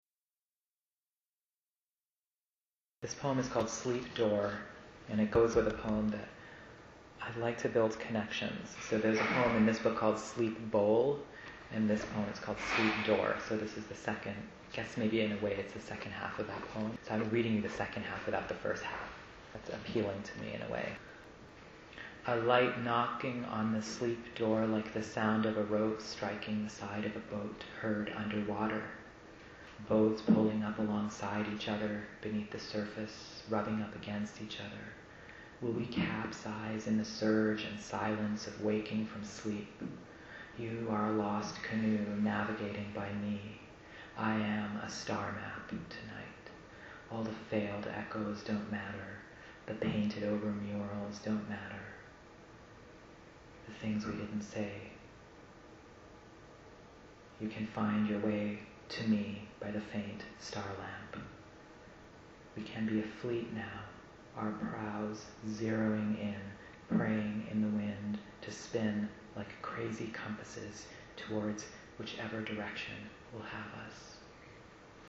Sleep Door (live) | Fishouse
ABOUT THE POET Kazim Ali
Sleep_Door_live.mp3